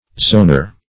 Search Result for " zonar" : The Collaborative International Dictionary of English v.0.48: Zonar \Zo"nar\, n. [Mod.